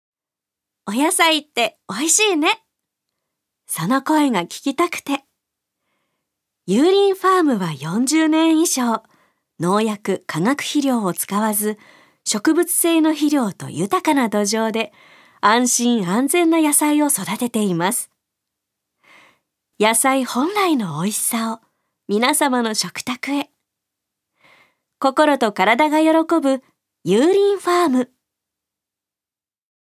ナレーション４